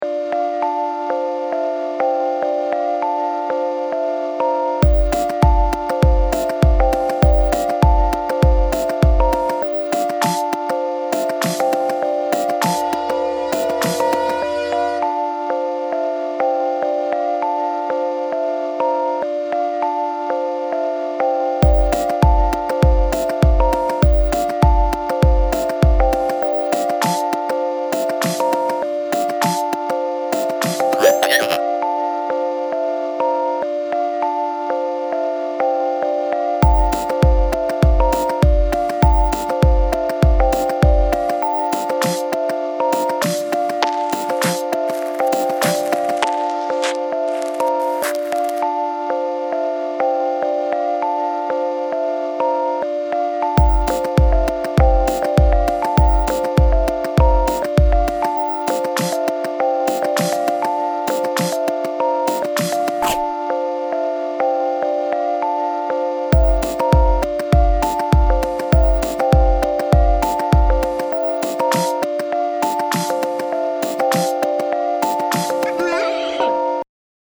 I already created background music files in Garage Band for other projects.
splitwit-demo-music.mp3